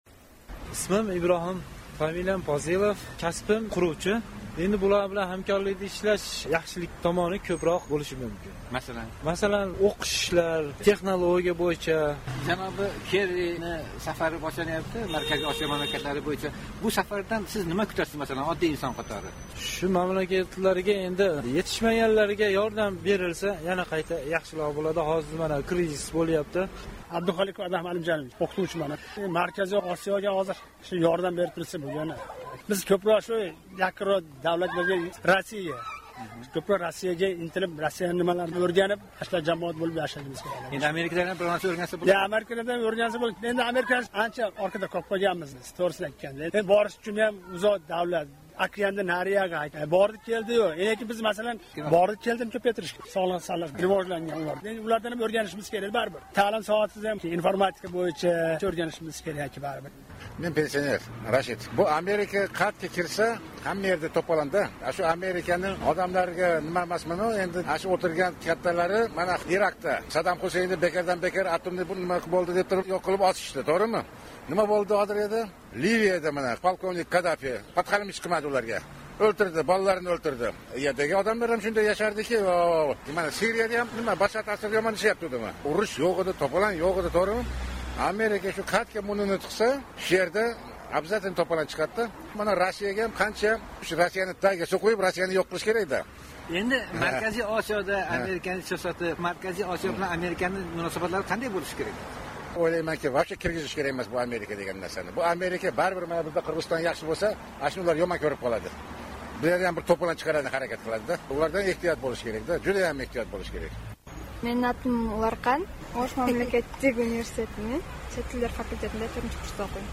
Kerrining Markaziy Osiyo safari haqida muloqot